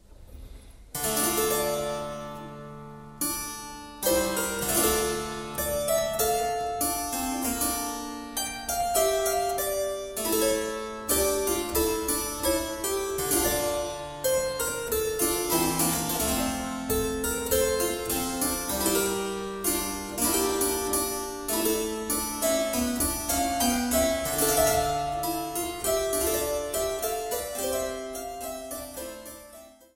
Virginal und Cembalo